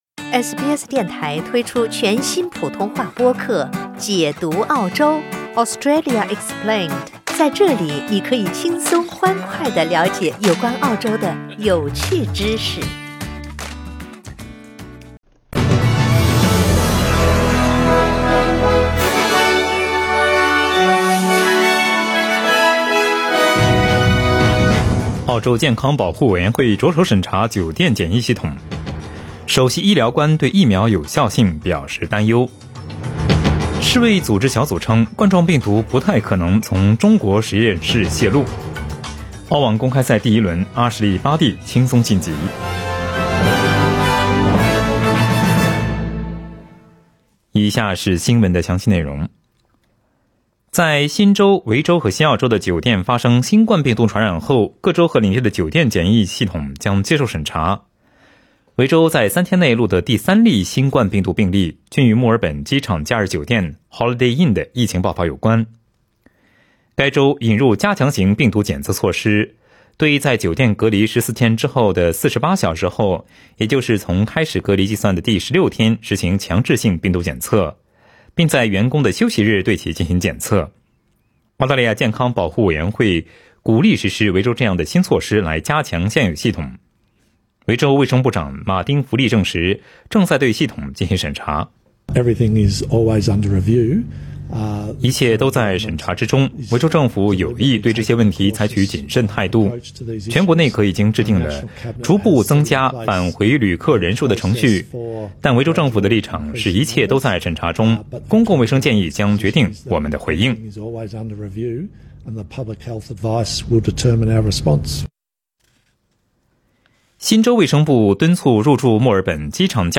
SBS早新聞（2月10日）